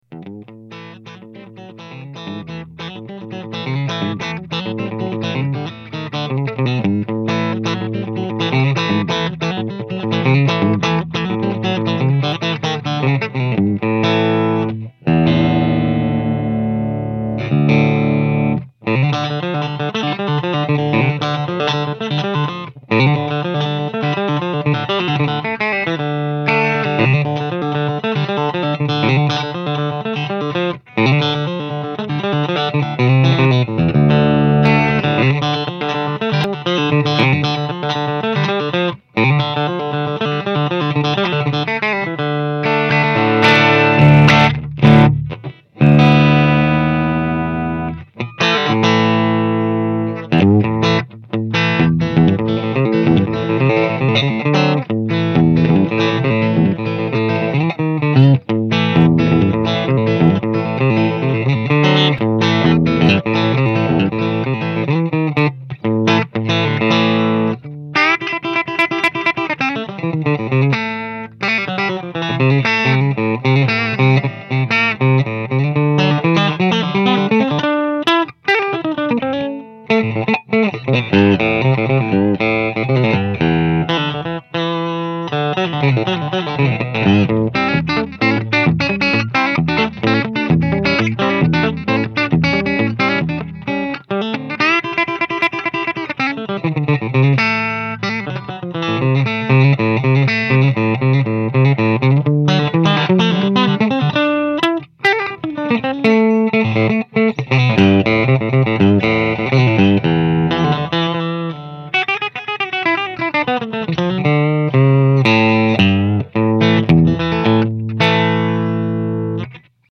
The Gypsy and Moosette are in prototype form and their specs are in the process of being refined. These clips were recorded with an SM-57 into my laptop's soundcard.
playing his Fender Tele w/ Texas pickups --